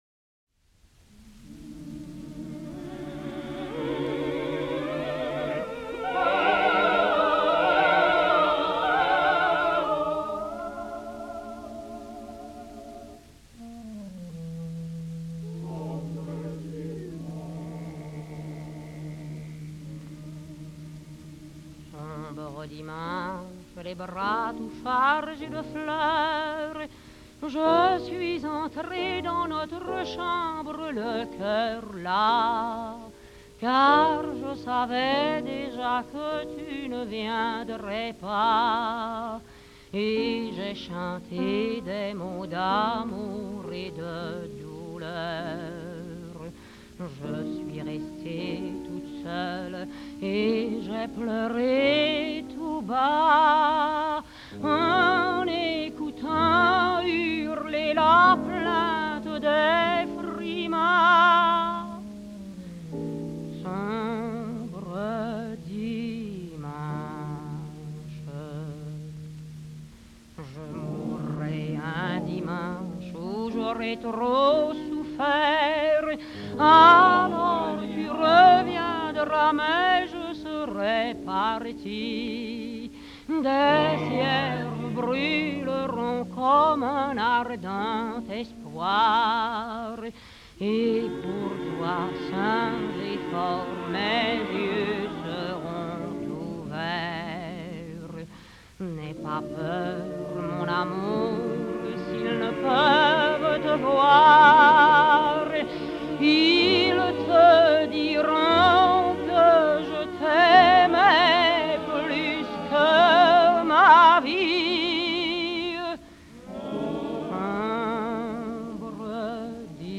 chanteuse réaliste